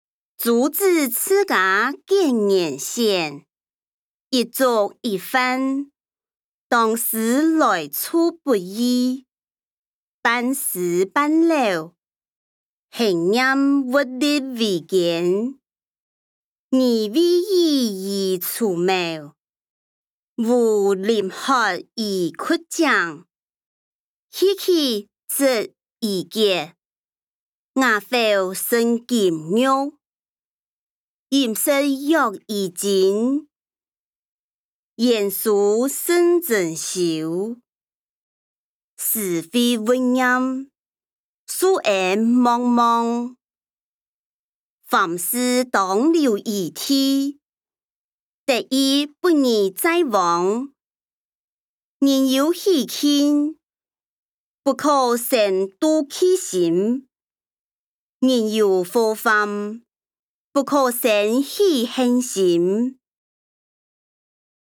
歷代散文-朱子治家格言選音檔(四縣腔)